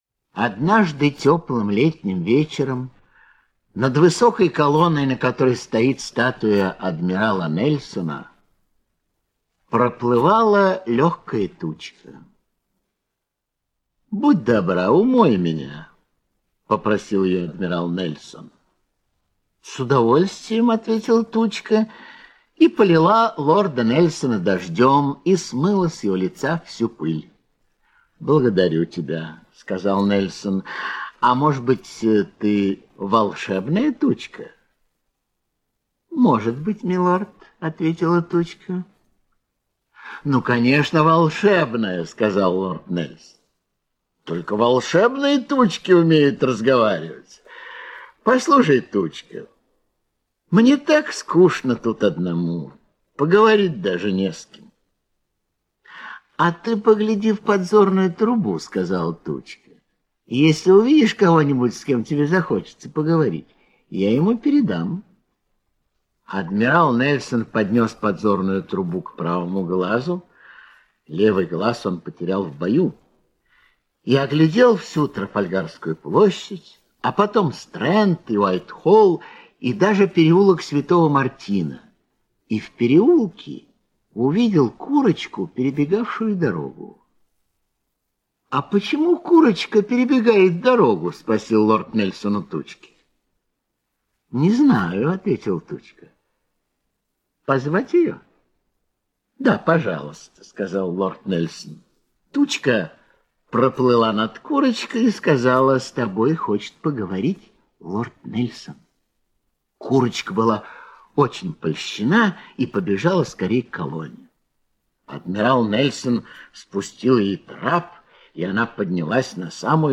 Нельсон и курочка - аудиосказка Биссета. Однажды теплым летним вечером над статуей адмирала Нельсона проплывала легкая тучка.